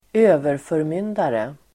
Ladda ner uttalet
Uttal: [²'ö:verfö:rmyn:dare]